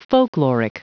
Prononciation du mot folkloric en anglais (fichier audio)
Prononciation du mot : folkloric